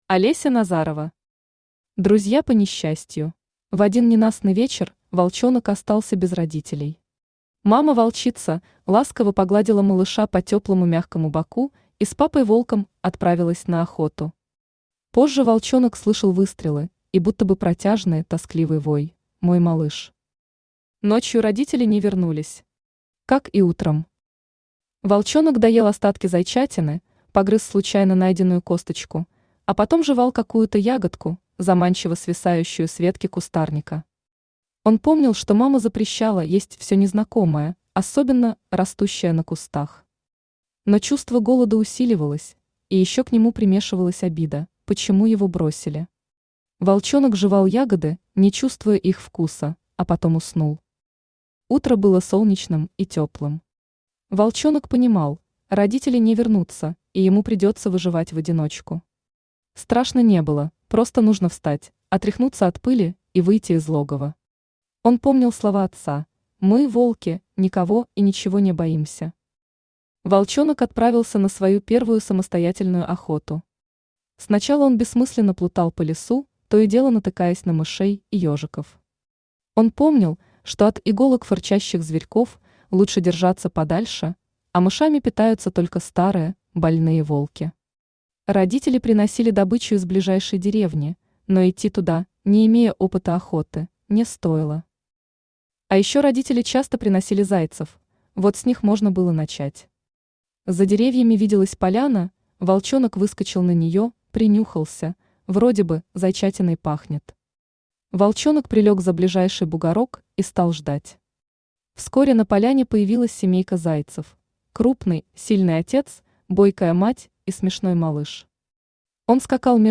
Аудиокнига Друзья по несчастью | Библиотека аудиокниг
Aудиокнига Друзья по несчастью Автор Олеся Назарова Читает аудиокнигу Авточтец ЛитРес.